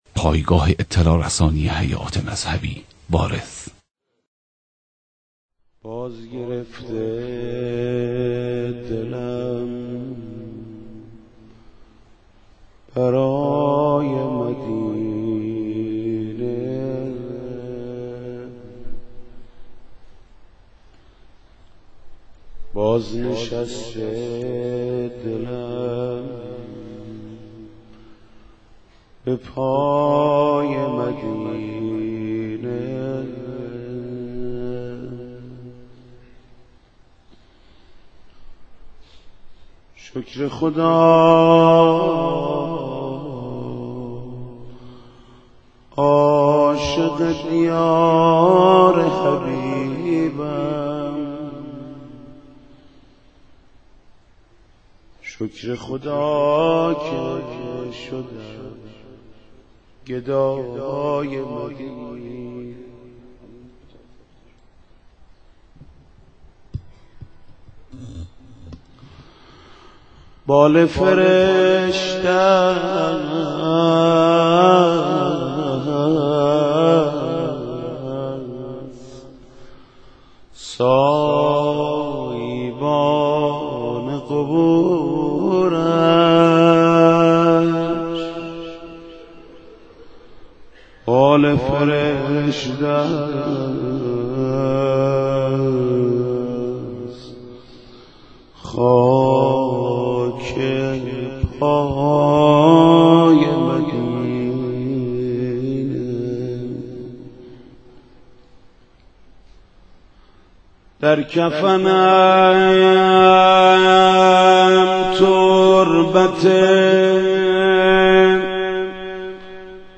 مداحی حاج محمود کریمی به مناسبت شهادت امام صادق (ع)